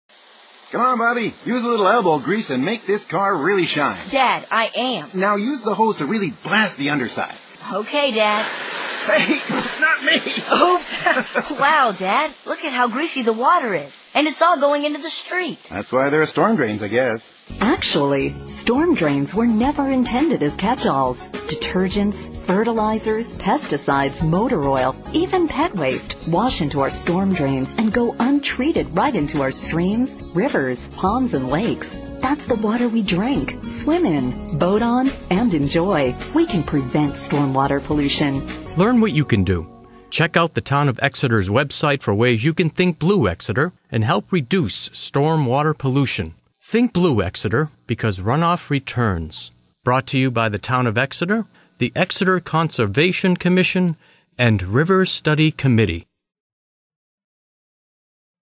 Car Wash Radio Ad (mp3 file) (896 KB)